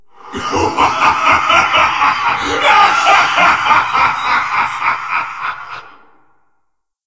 UltimateLaughter.ogg